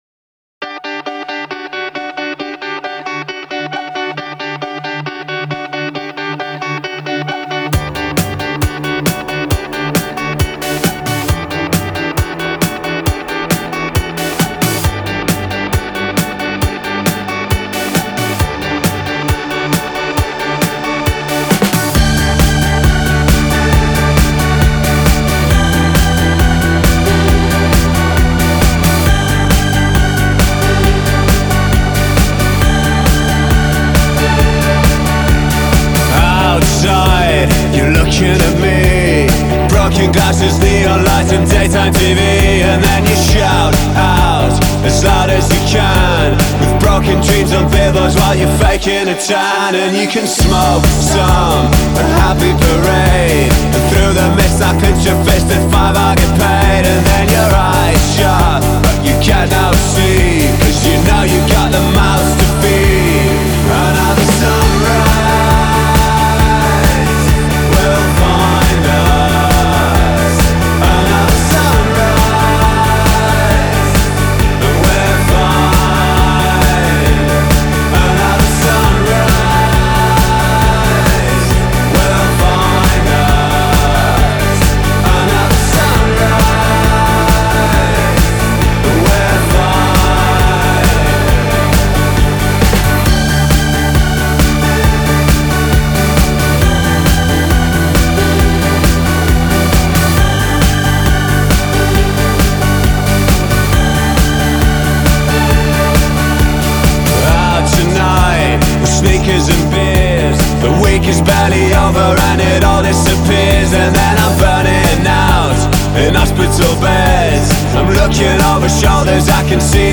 Стиль: House, Downtempo, Electronic